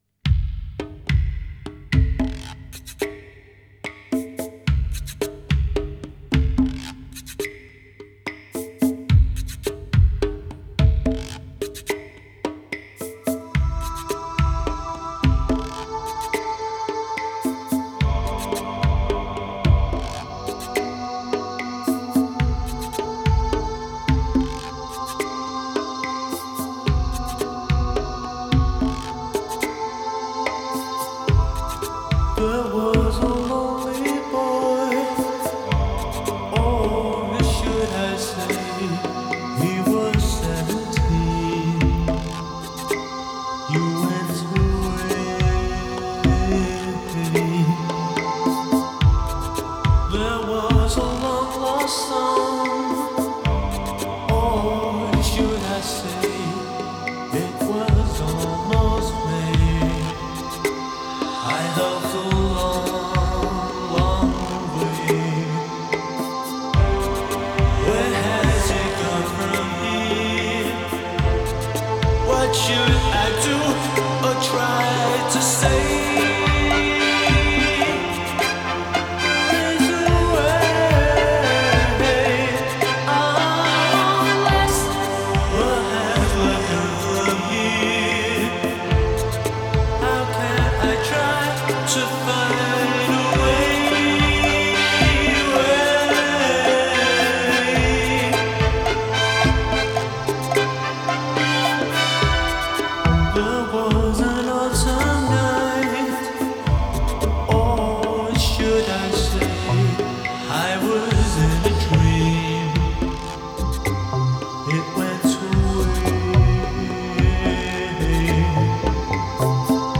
Genre: Indie, Lo-Fi, New Wave